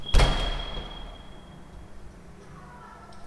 • door closing hallway echo.wav
Apartment door closing, recorded in a hallway, full of natural stone, using a Tascam DR 40.
door_closing_hallway_echo_vff.wav